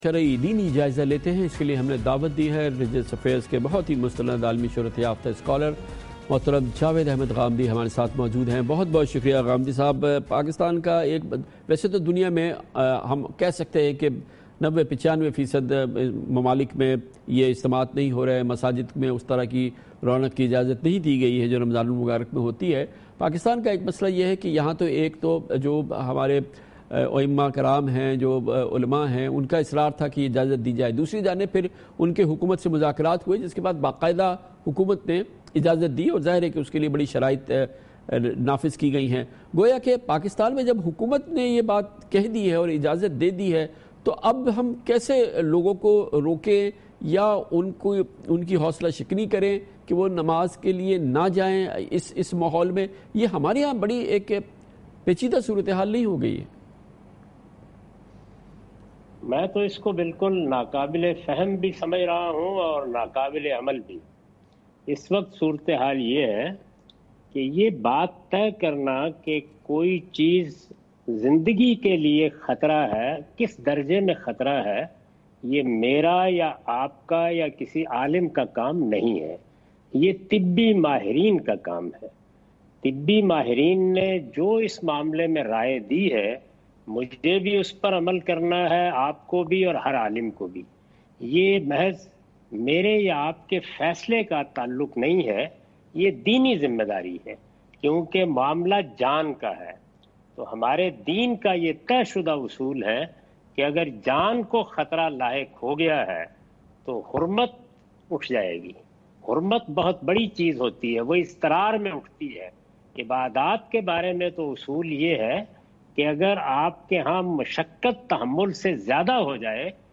Category: TV Programs / Dunya News / Questions_Answers /
Javed Ahmad Ghamidi answers some important question about corona virus on Dunya News, 24 April 2020.